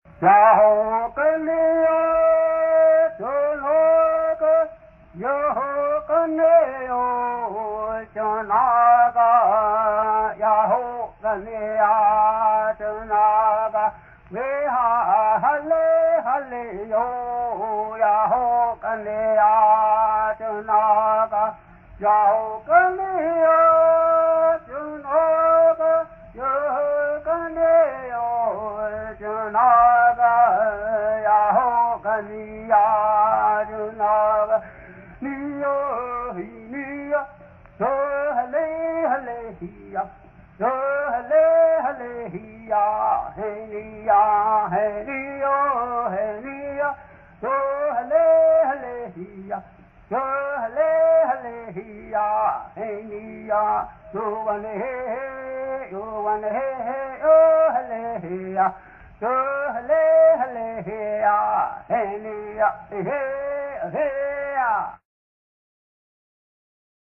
동부 부족 출신 체로키 원어민 녹음